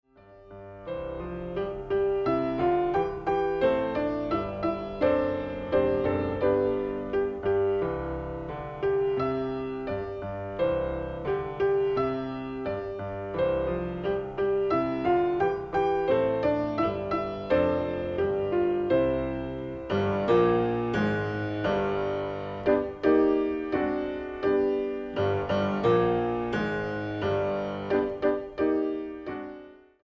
Piano/Organ Ensembles Piano Duets
Piano Duet